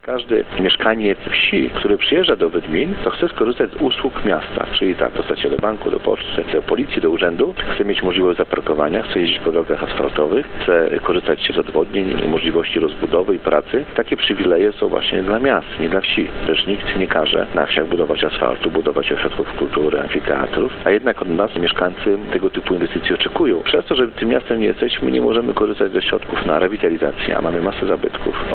Mieszkańcy zdecydują, czy samorząd powinien starać się o przyznanie statusu miasta, czy pozostać przy statusie wsi. – Wydminy miały prawa miejskie na krótko po II wojnie światowej, później ta sytuacja się zmieniła – mówi wójt gminy, Radosław Król.